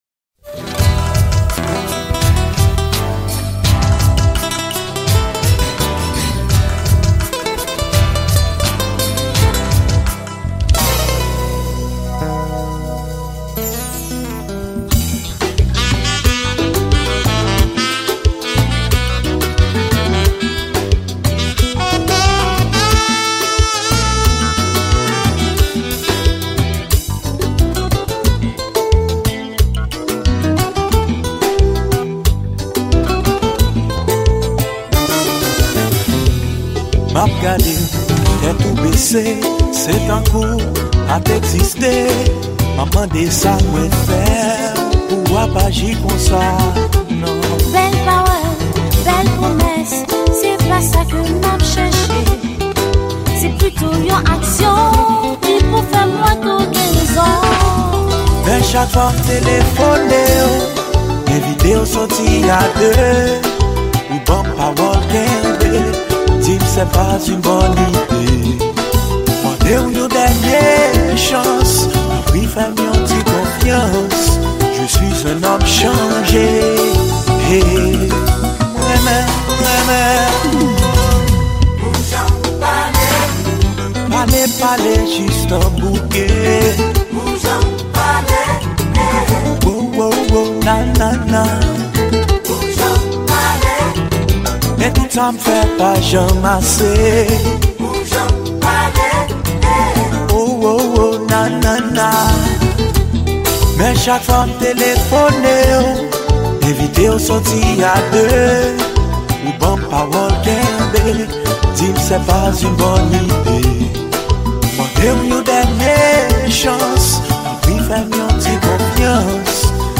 Genre: Konpa